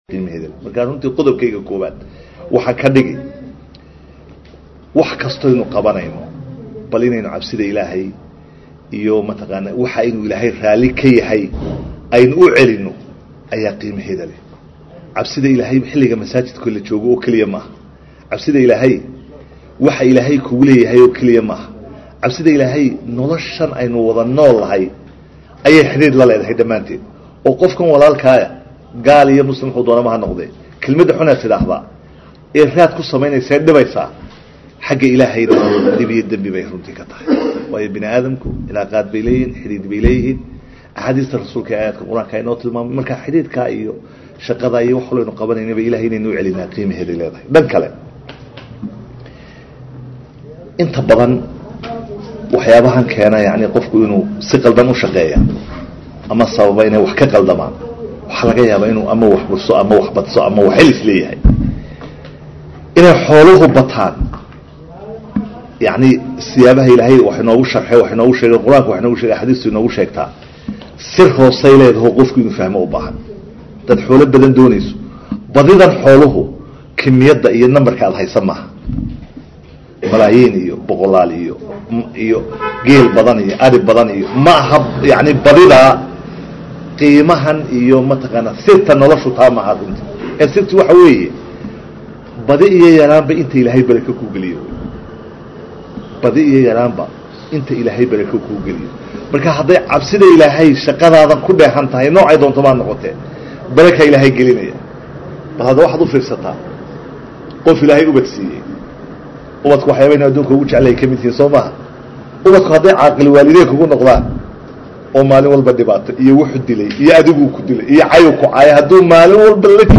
Hargaysa 31.March 2014 (SDN)- Wasiirka Wasaarada Maaliyada Mudane C.casiis Maxamed Samaale ayaa Maanta Hadal ka Jeediyay Kulan Ay Ka Qayb Qaadanayeen Qaar Ka Tirsan Wasaaradaha Dawlada Iyo Nootaayooyinka Sharciga Soona Isla markaana ay Wasaaradiisu soo Qaban qaabisay Ujeedadiisuna la Xidhiidhay Kob Cinta Cashuuraha oo ka qabsoomay Hoolka Shirarka ee Hay’ada Shaqaalaha Dawlada.